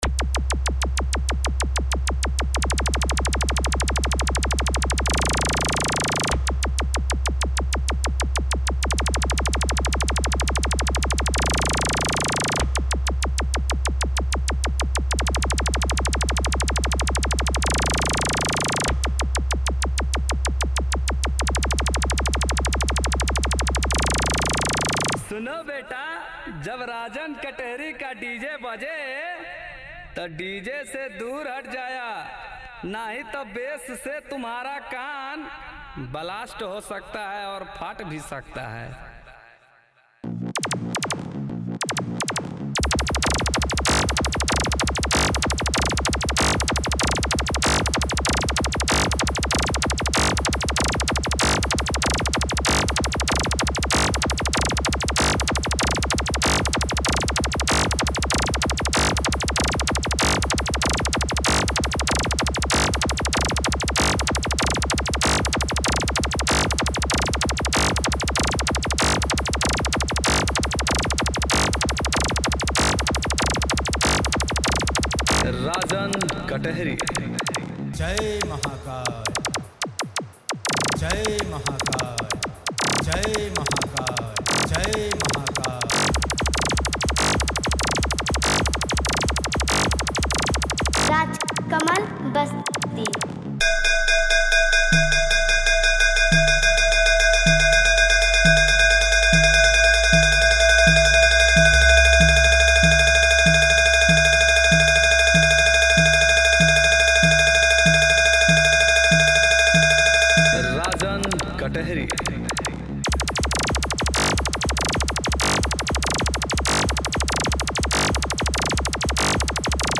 DJ Competition Songs